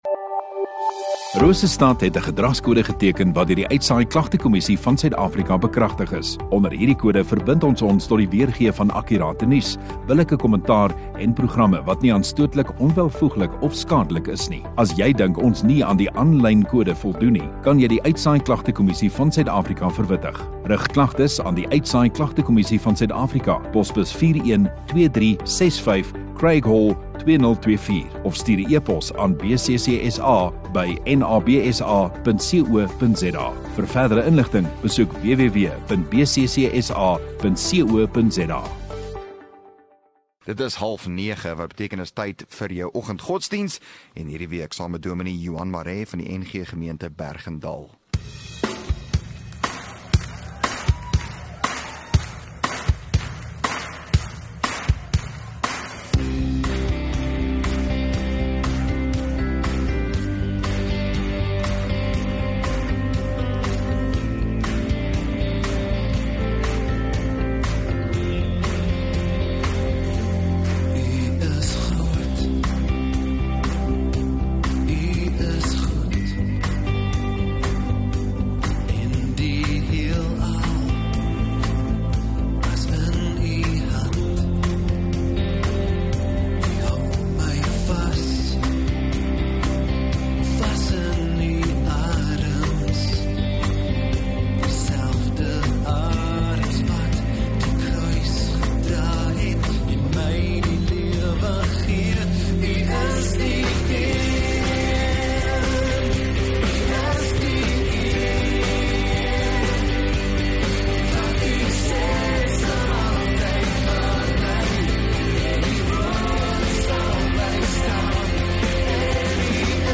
View Promo Continue Radio Rosestad Install Rosestad Godsdiens 4 Dec Donderdag Oggenddiens